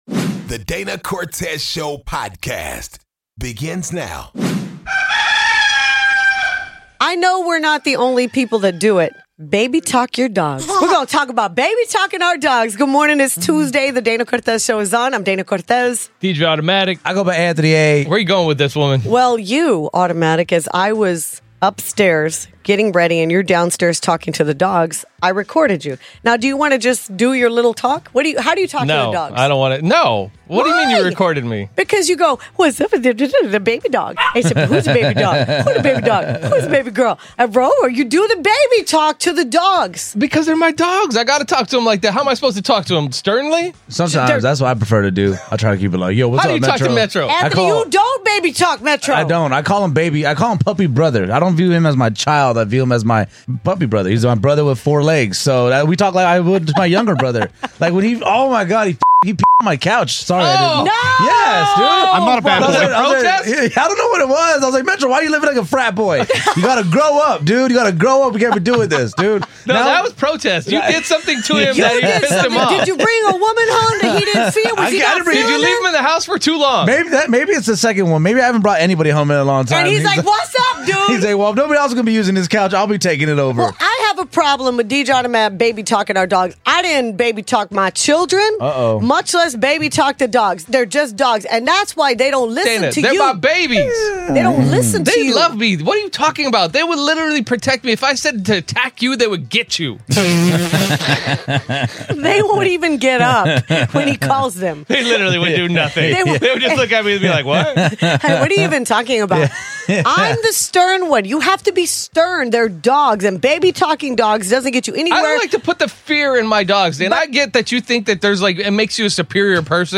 Blended families is the new norm in America. We had a listener call in and say that we were ruining families by normalizing it!